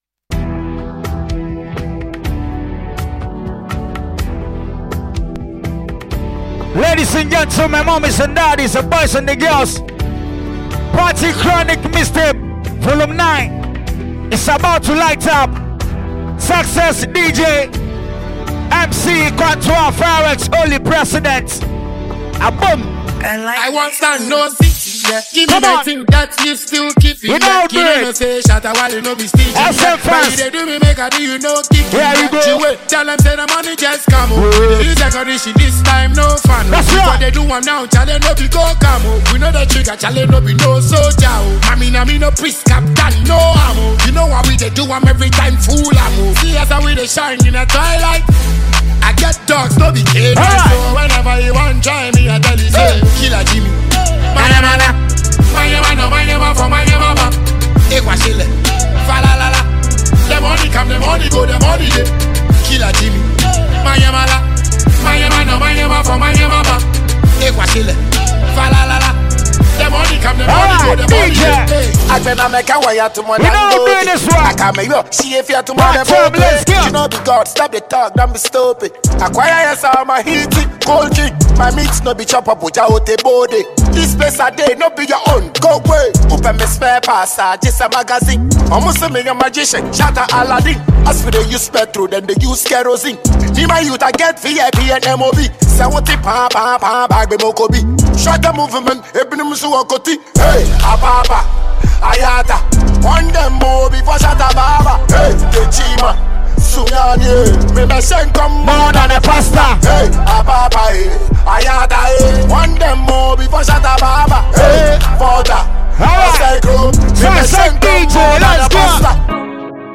captivating vocals